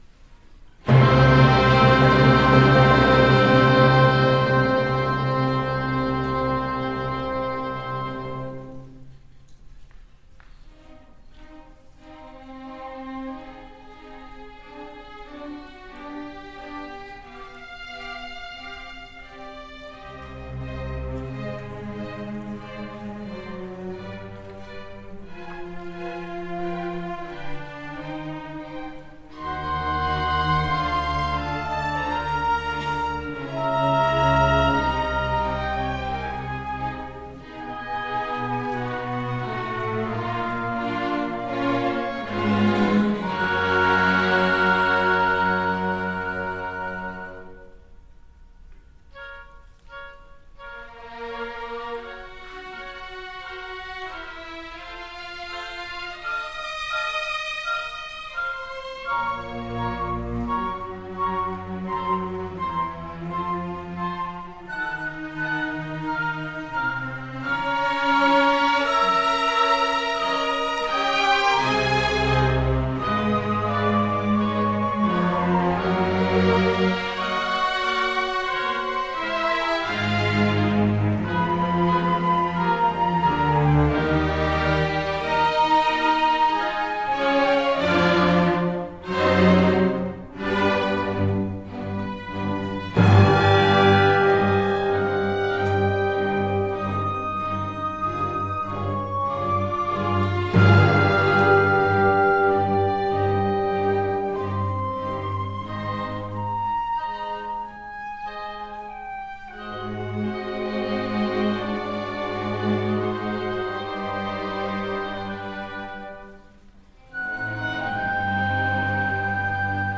TL notes: Schubert’s Symphony No. 4 in C minor (performed by the Young Artist Orchestra of Greenville)II.
Symphony-No.4-in-C-minor-Tragic-Mvt.-1-by-Franz-Schubert.mp3